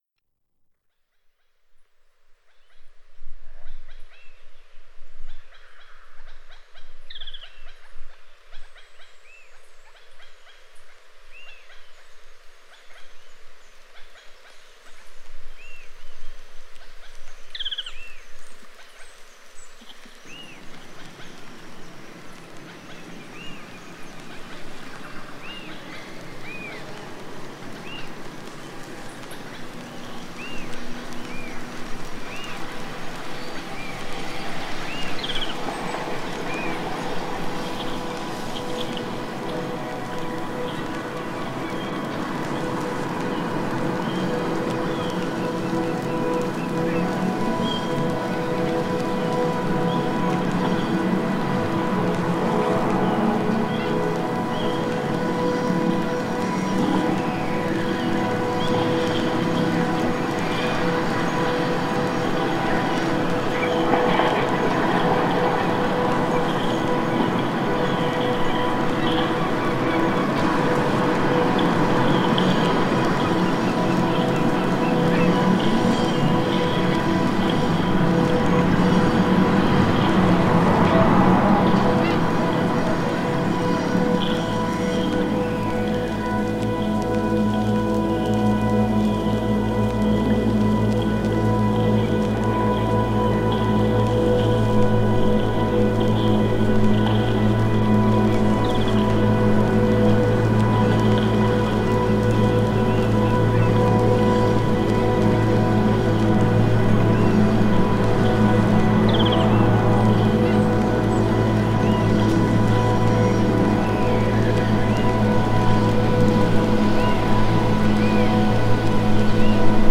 Genre: Ambient/Drone/Field Recordings.